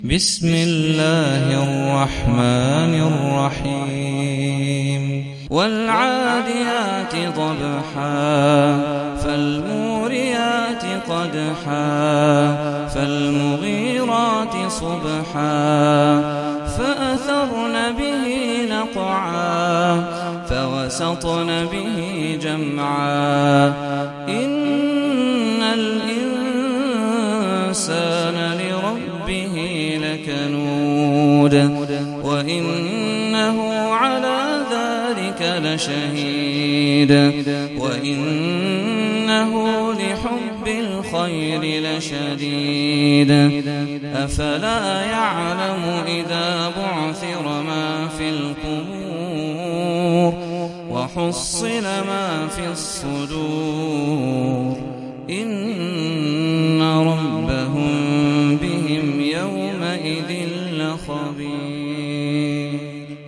سورة العاديات - صلاة التراويح 1446 هـ (برواية حفص عن عاصم)